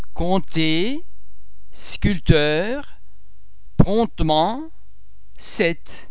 The French [p] tends to be silent before the letter [t]: